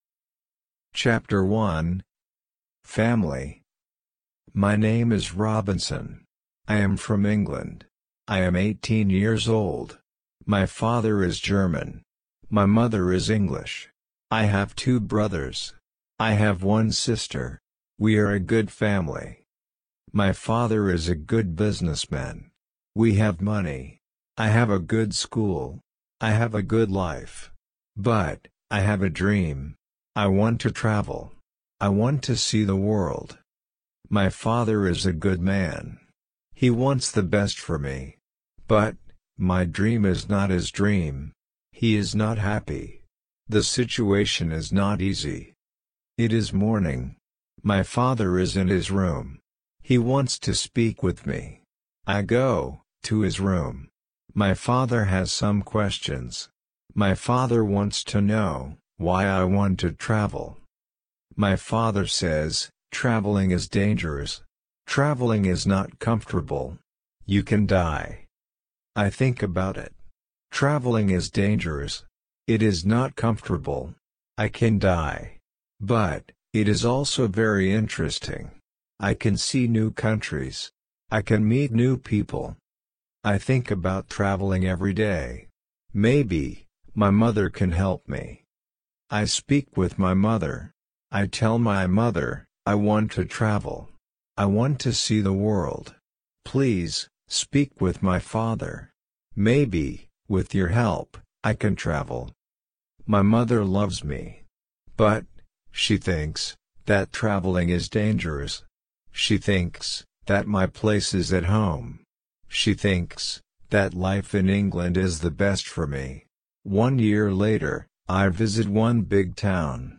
RC-L1-Ch1-slow.mp3